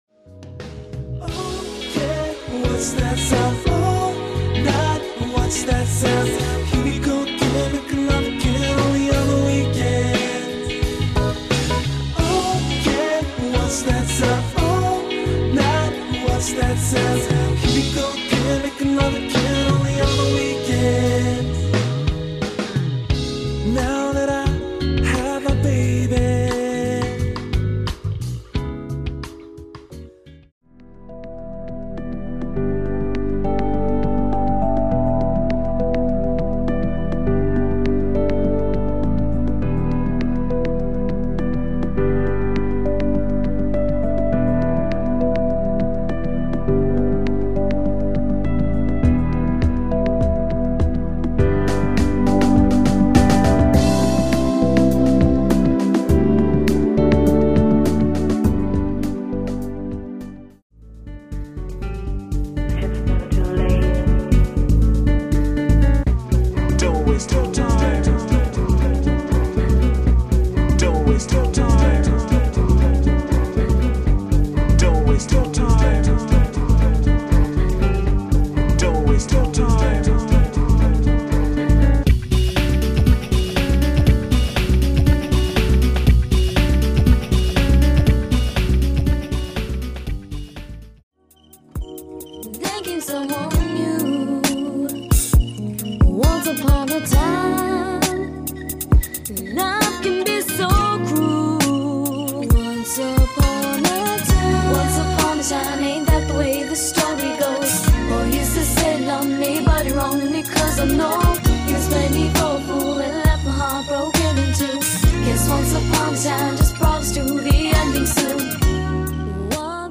styl - pop/smooth jazz/chill